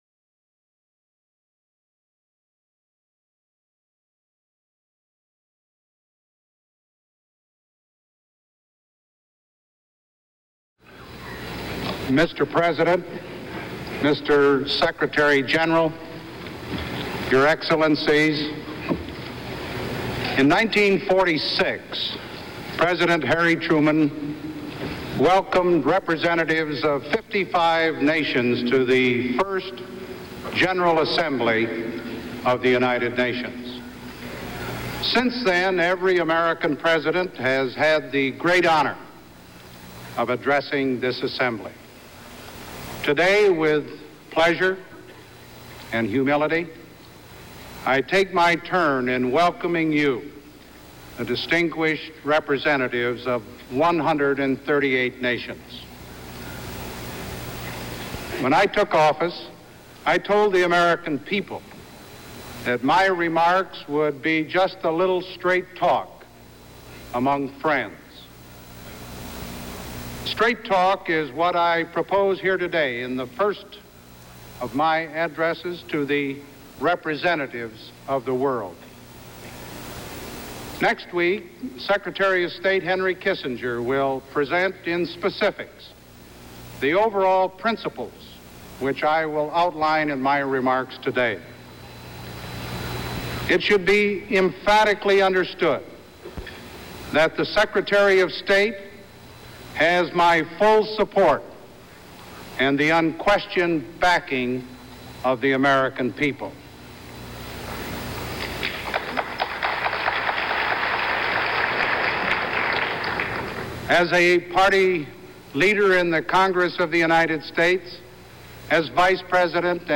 September 18, 1974: Address to the General Assembly of the United Nations